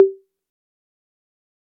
click-short.wav